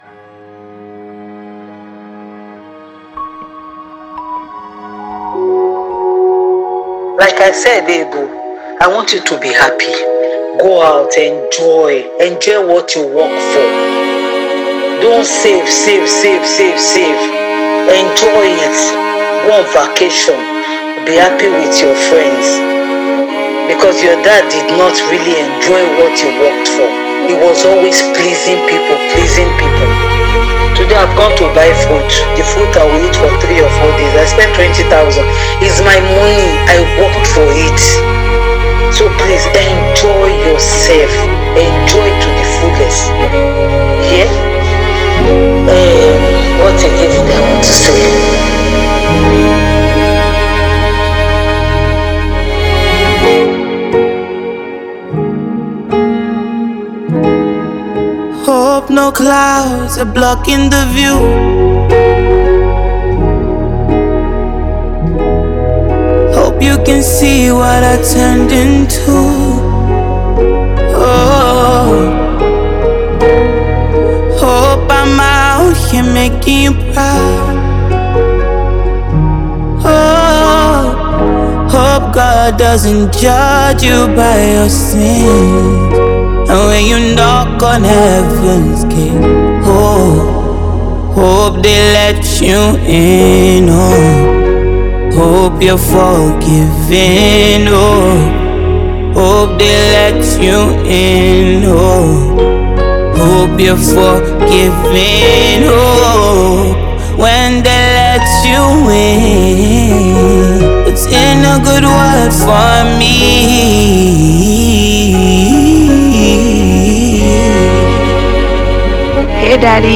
Phenomenal Nigerian singer-songwriter
Genre: Afrobeats